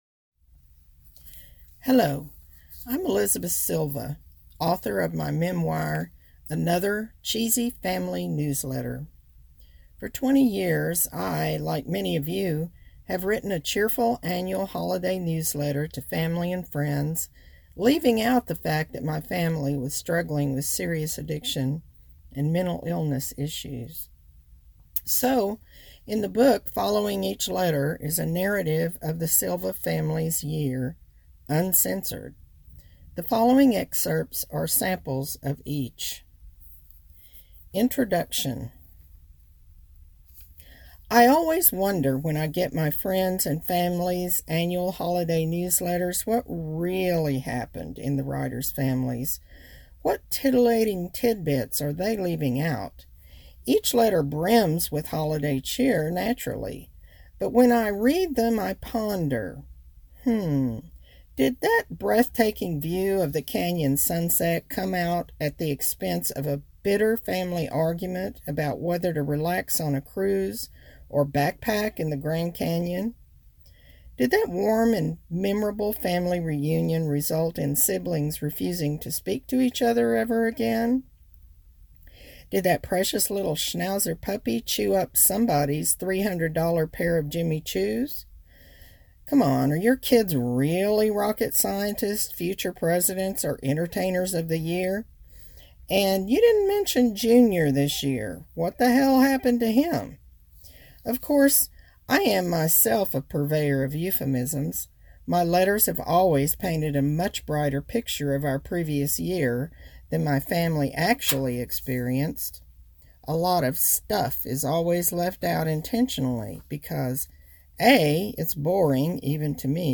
An excerpt reading